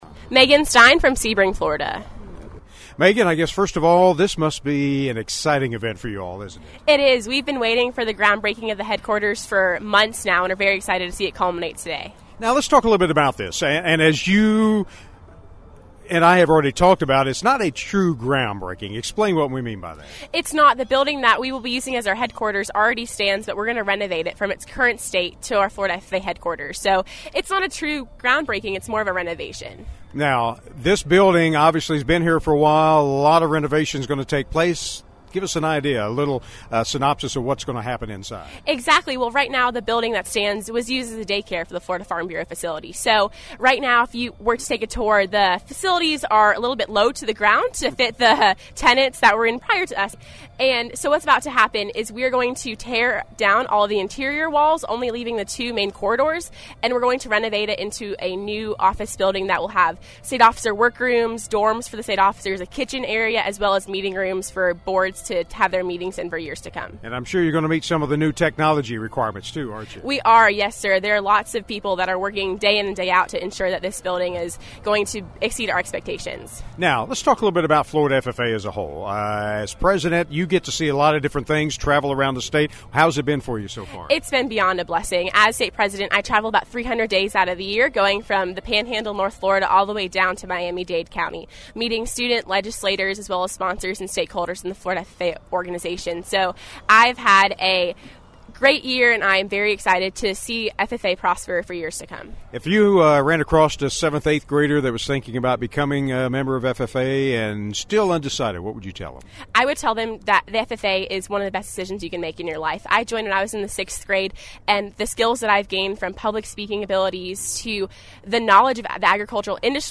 A large contingent of dignitaries were on hand this morning for the groundbreaking of construction for the new Florida FFA state headquarters in Gainesville.